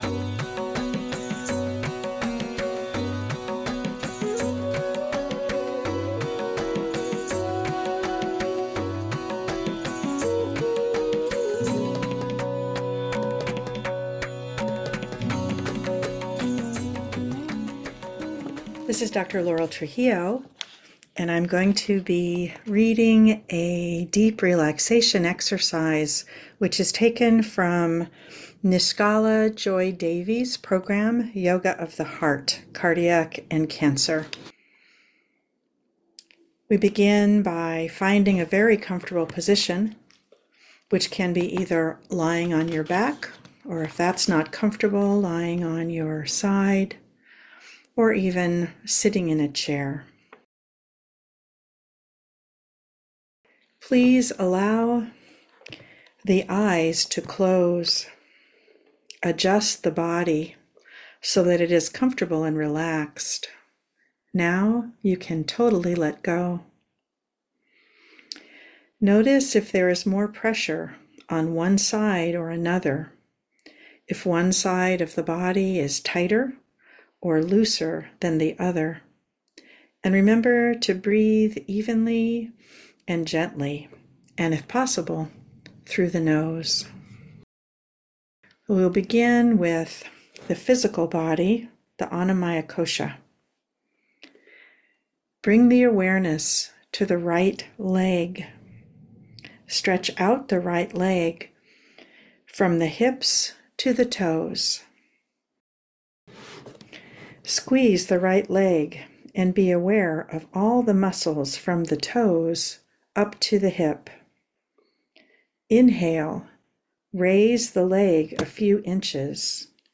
Deep Relaxation Meditation.mp3